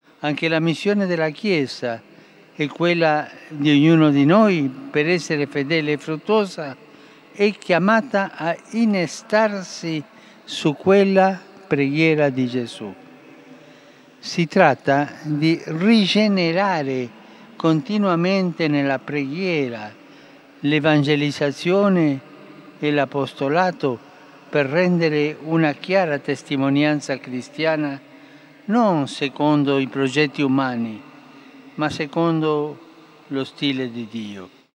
W rozważaniu przed modlitwą „Anioł Pański” w święto Chrztu Pańskiego, w Watykanie, papież Franciszek nawiązał do fragmentu Ewangelii ukazującego dwa ważne elementy: związek Jezusa z ludźmi i związek Jezusa z Ojcem.